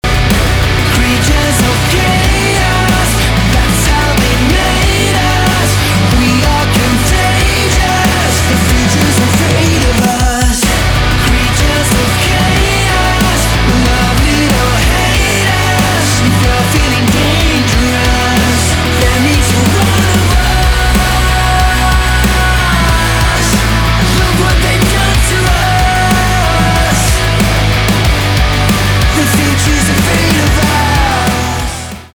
альтернатива
гитара , барабаны , громкие